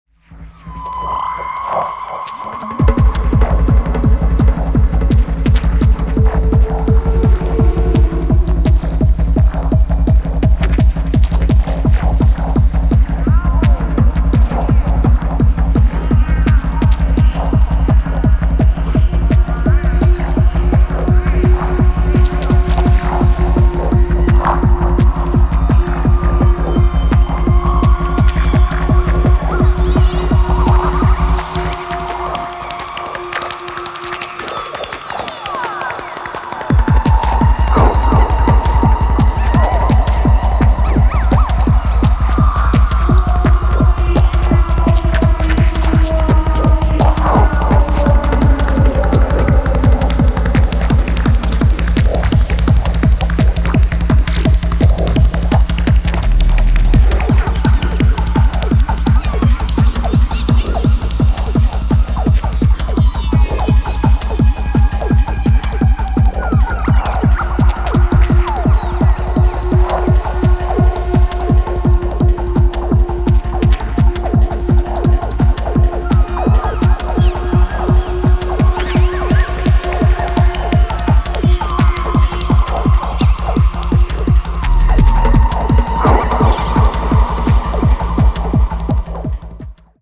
初期のスローモーなグルーヴからは装いを変えて、今回は速いです。
持ち味のトライバルなパーカッションも相まって非常にグルーヴィー、強力無比です。